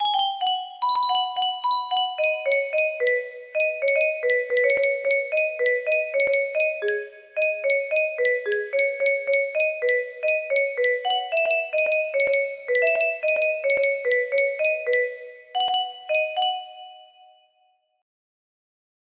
music box melody